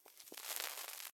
cig_light.ogg